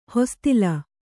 ♪ hostila